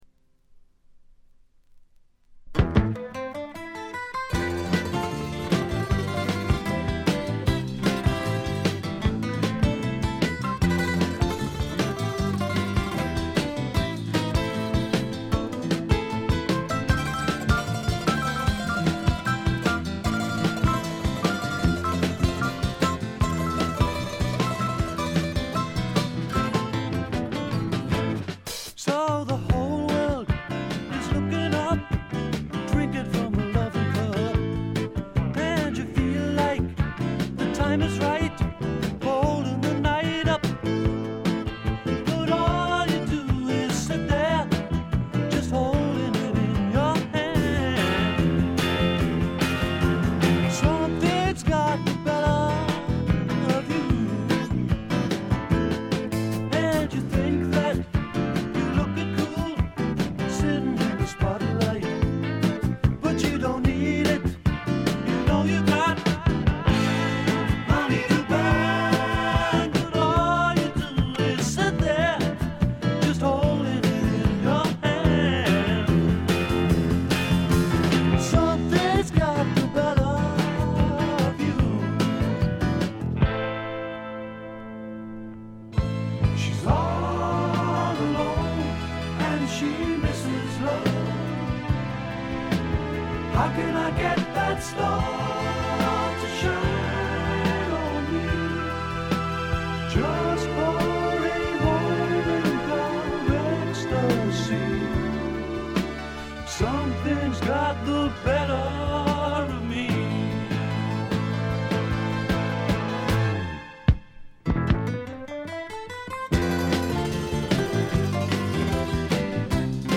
軽いチリプチ少々。
パブロック風味満載、いぶし銀の英国フォークロックです。
試聴曲は現品からの取り込み音源です。